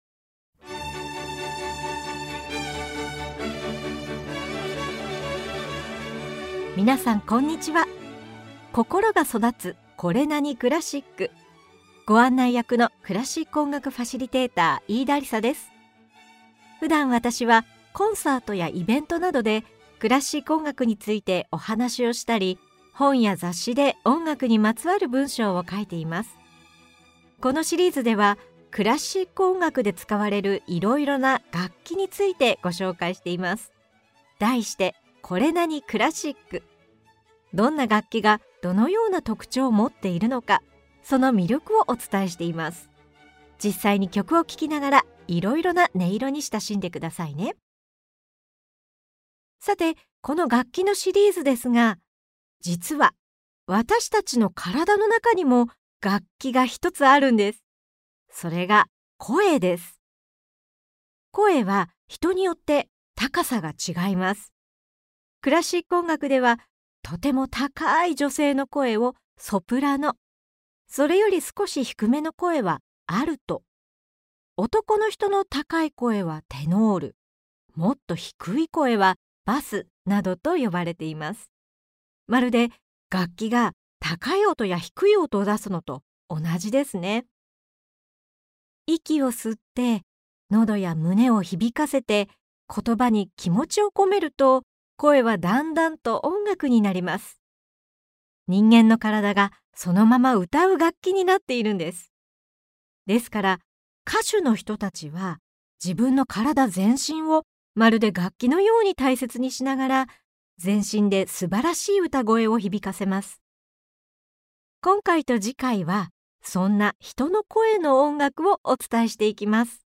実際に曲を聴きながら、いろんな楽器とその音色に親しんでください。